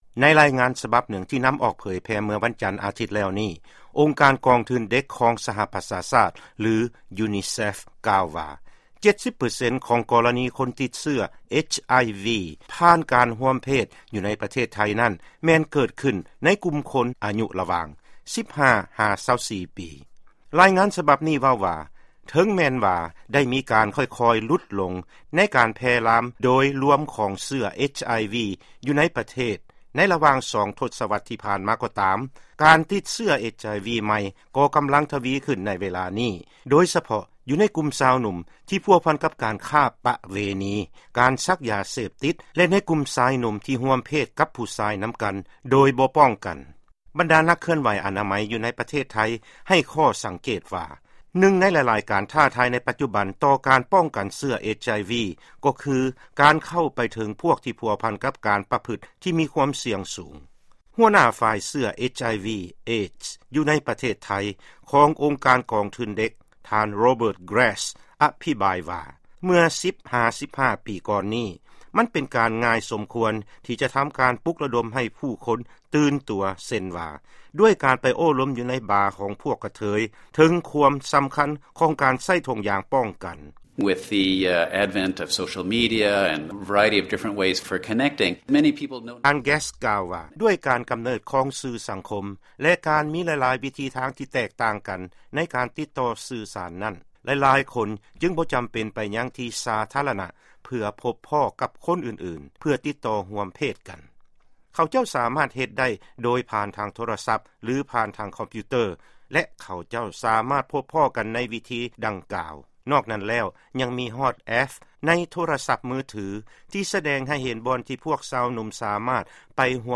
ມີກໍລະນີ ຕິດເຊື້ອ HIV/AIDS ເກີດຂື້ນໃໝ່ ໃນກຸ່ມຊາວໜຸ່ມໄທ (ຟັງລາຍງານເປັນພາສາອັງກິດຊ້າໆ)
ຟັງລາຍງານນີ້ ເປັນພາສາອັງກິດຊ້າໆ ເພື່ອຮຽນໄປພ້ອມ: